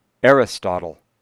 [ˌæləˈgejʃən] allegation [ˈæləˌgejɾɚ]  alligator [ˈɛɹəsˌtɑtl̩]